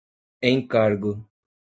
Pronunciat com a (IPA)
/ẽˈkaʁ.ɡu/